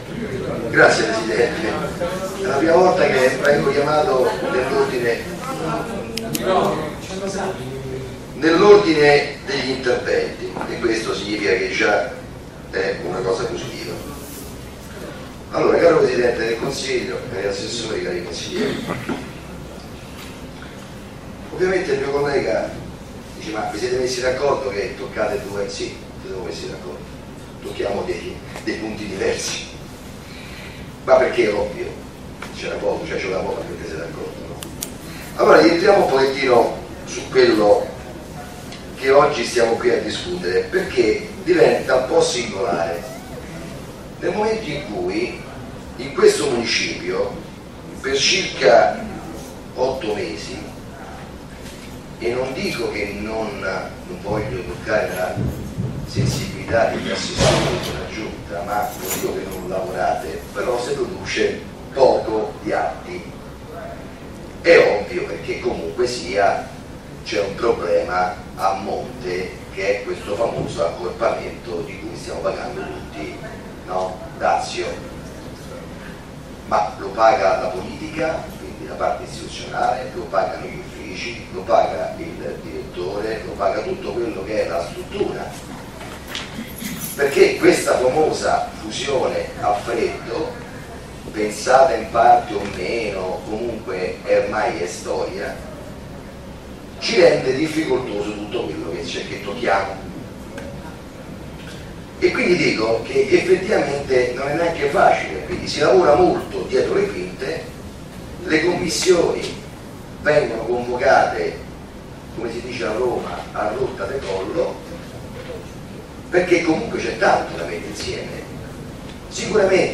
Seduta del Consiglio Municipale Roma VII (Ex IX e X) del 21 febbraio 2014, Sala Consiliare P.zza di Cinecittà, 11